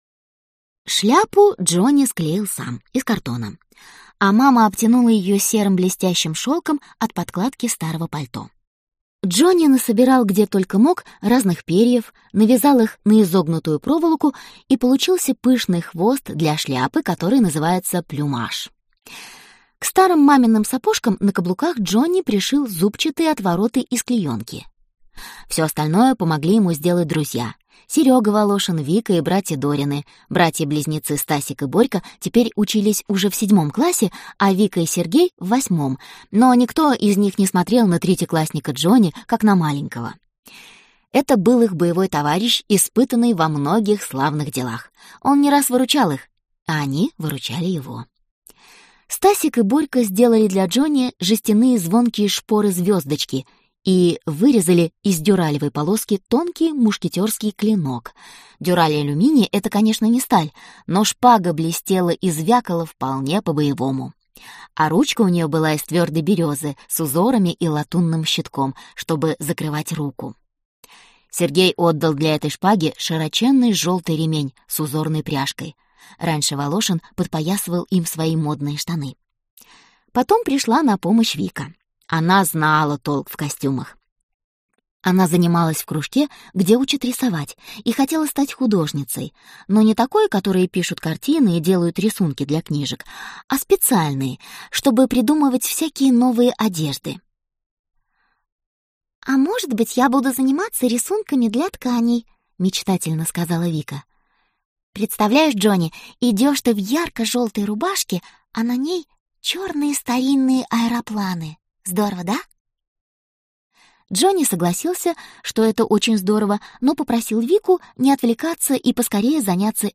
Аудиокнига Мушкетёр и фея. Часть 1 | Библиотека аудиокниг